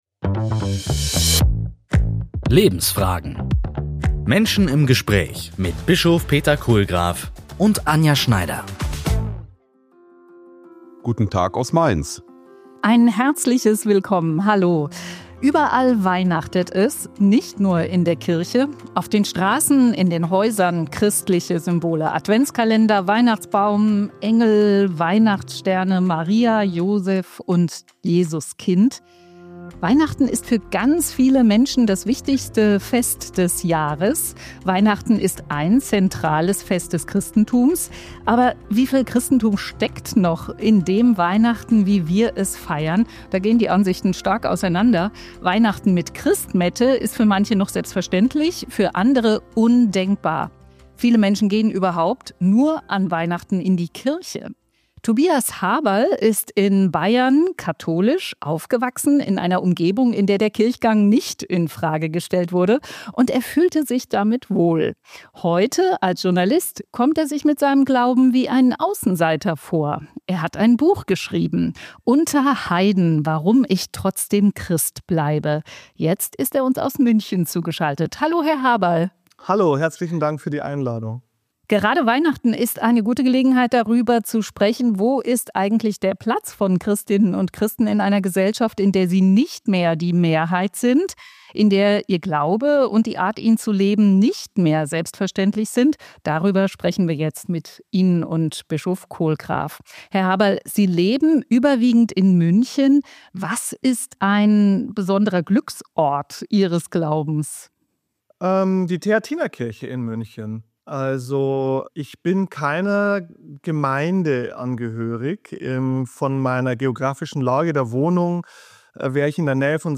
Lebensfragen – Menschen im Gespräch